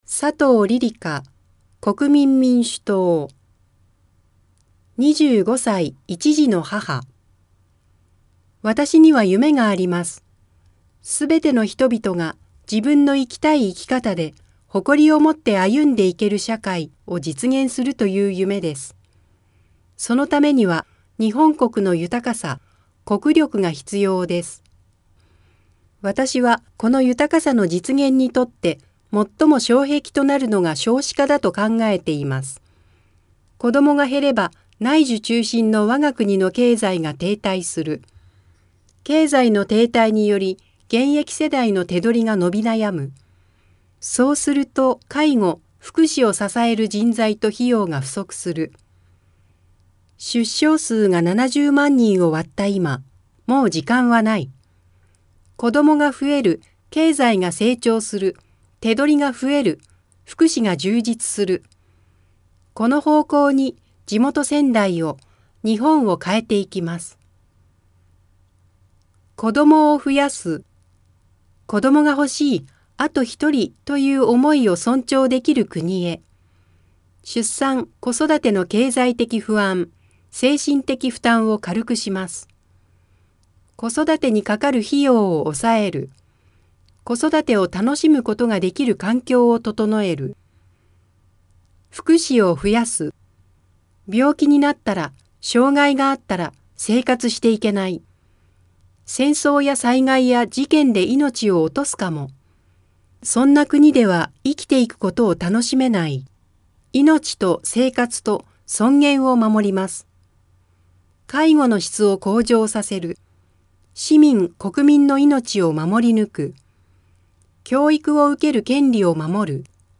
衆議院議員総選挙候補者・名簿届出政党等情報（選挙公報）（音声読み上げ用）